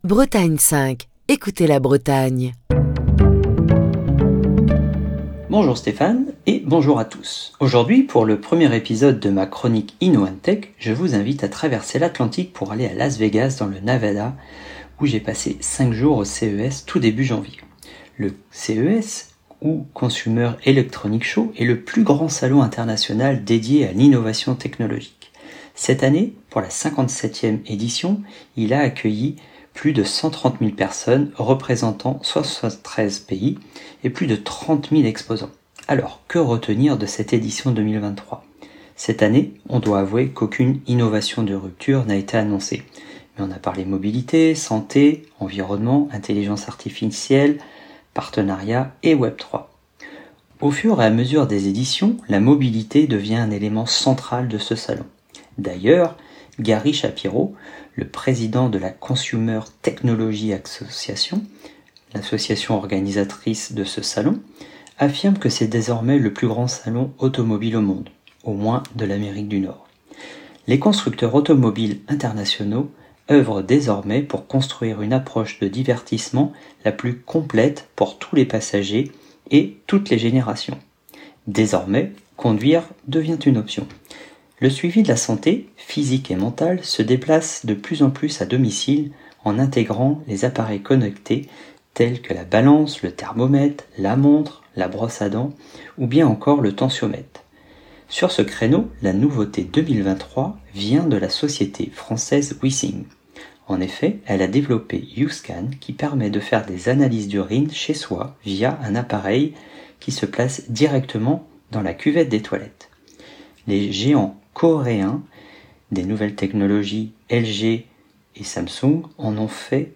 Chronique du 1er février 2023.